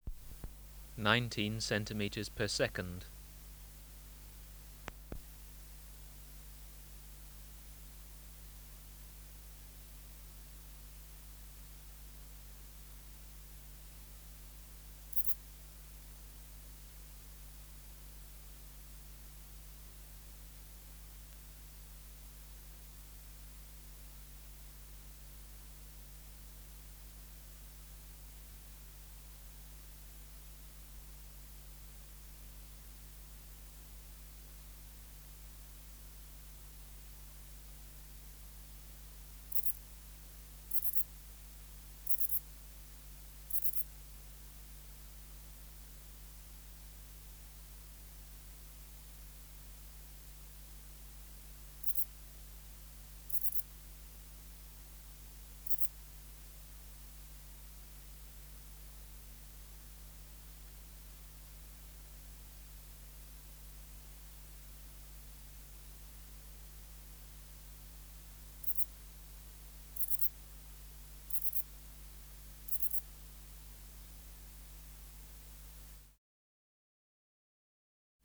Project: Natural History Museum Sound Archive Species: Ephippiger ephippiger
Recording Location: BMNH Acoustic Laboratory
Substrate/Cage: Recording cage
Microphone & Power Supply: Sennheiser MKH 405 Distance from Subject (cm): 30